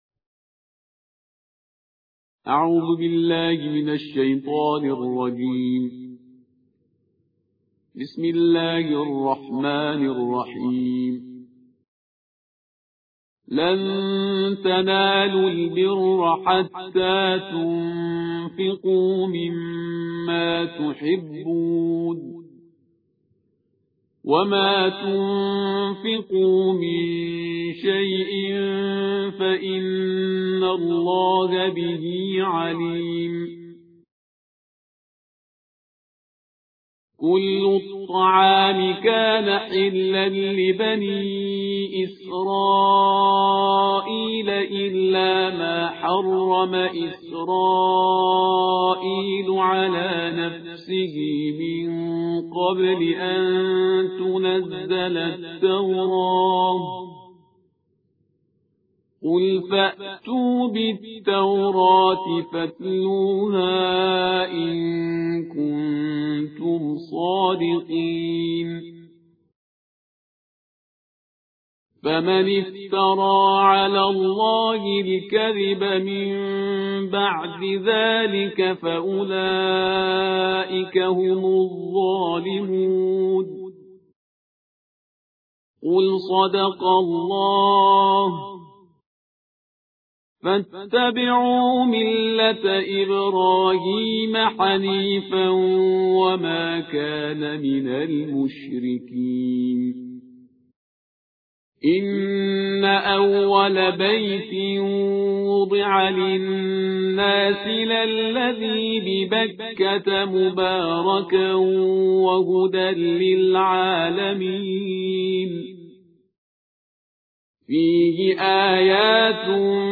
صوت | ترتیل‌ جزء بیست و پنجم قرآن کریم